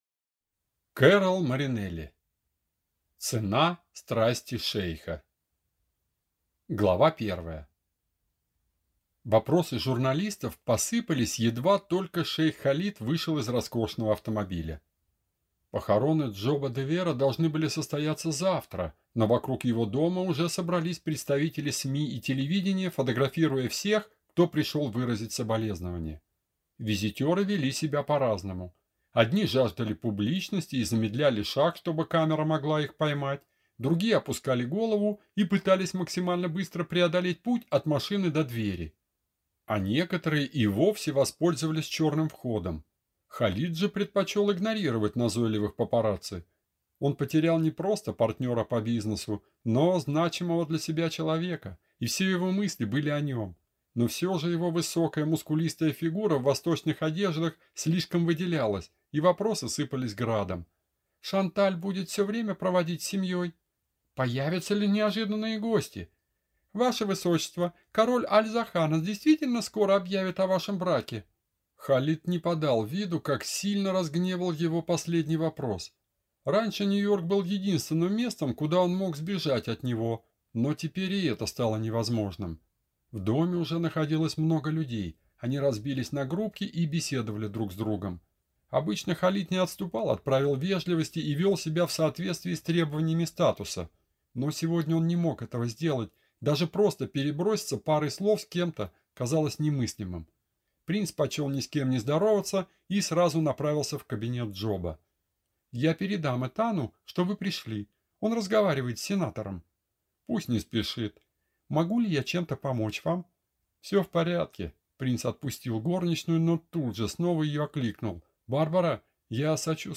Аудиокнига Цена страсти шейха | Библиотека аудиокниг